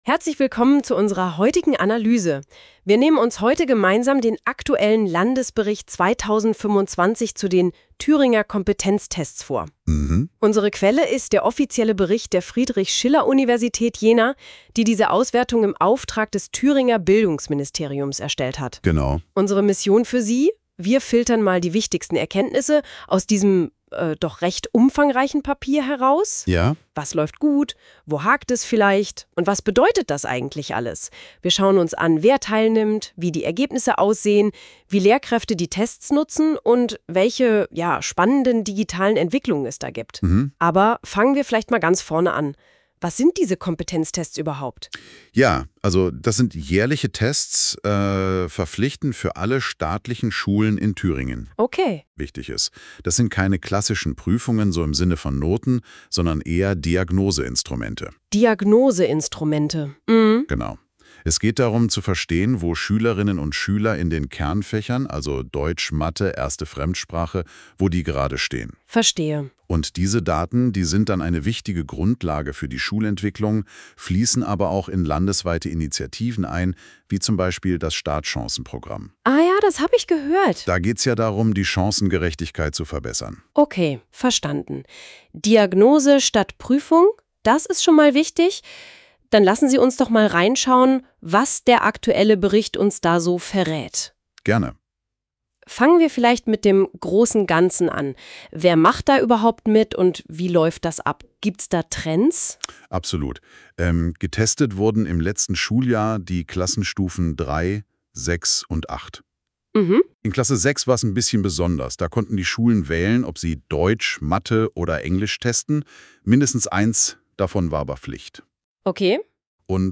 Dieser Podcast wurde mit Künstlicher Intelligenz generiert und kann wenige Unstimmigkeiten enthalten.